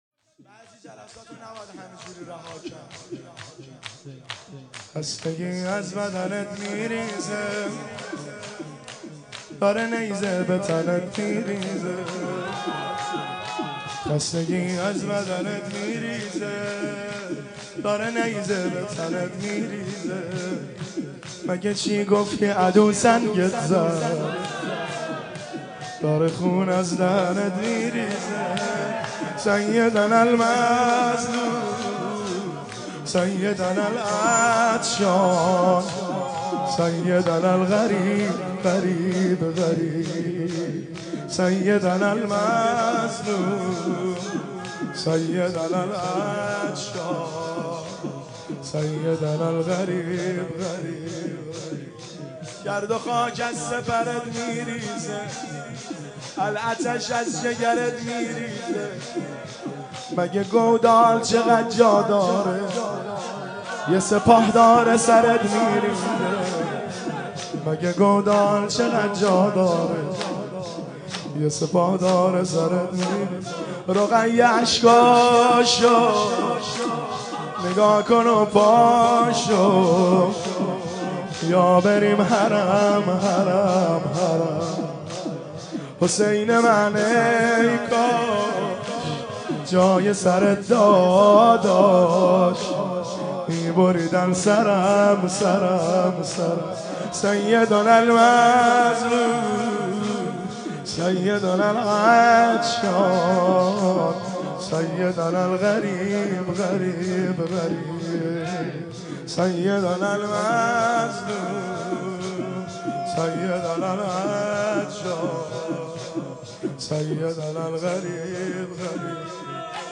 مداحی و نوحه
مداحی فاطمیه ۱۳۹۶
(شور)